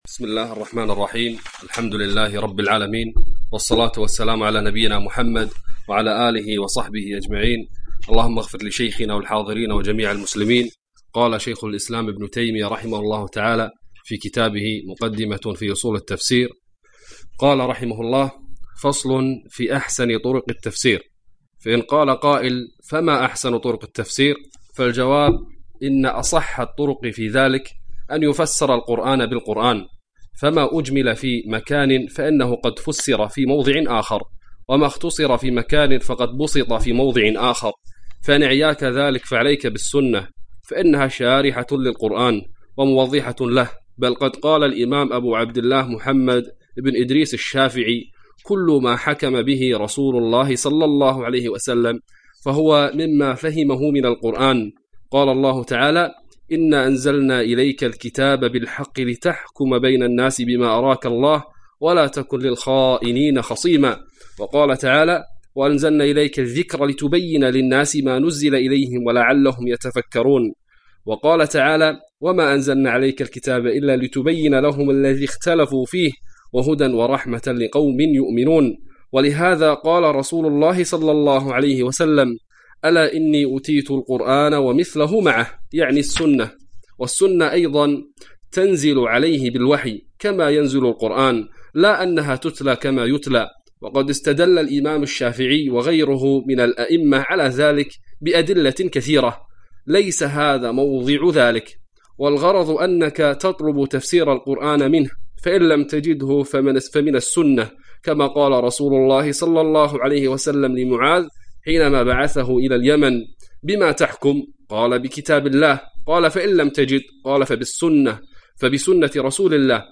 الدرس التاسع : فصل في أحسن طرق التفسير